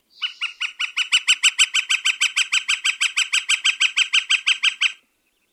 This is a beginner-level comparison group of rattle-like bird songs and sounds from Land Birds of North America, Eastern/Central region.
Northern Flicker - Rattle call
Slow and level-pitched with laughing quality.